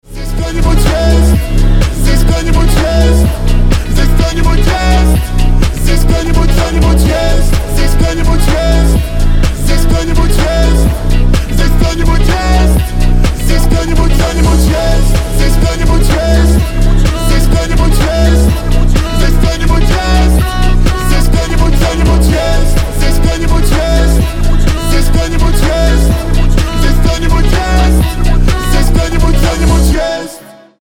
• Качество: 320, Stereo
мужской голос
русский рэп